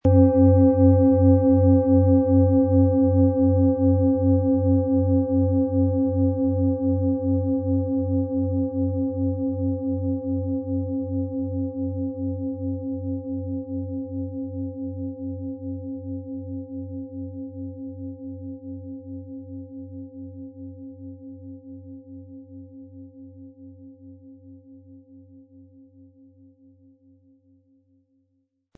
• Mittlerer Ton: Biorhythmus Körper
• Höchster Ton: Lilith
PlanetentöneJupiter & Biorhythmus Körper & Lilith (Höchster Ton)
MaterialBronze